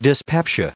added pronounciation and merriam webster audio
955_dyspeptic.ogg